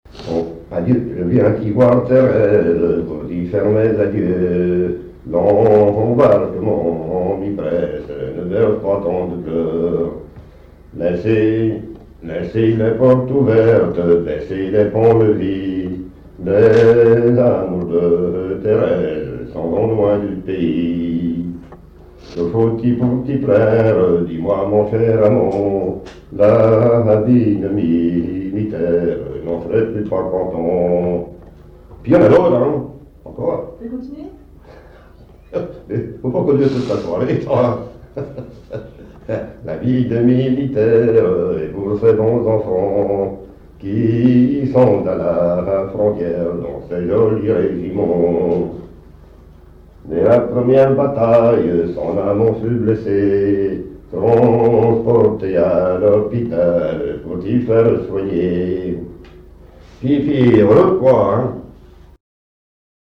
chant de conscrits
Genre strophique
Pièce musicale inédite